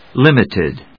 音節lim・it・ed 発音記号・読み方
/límɪṭɪd(米国英語)/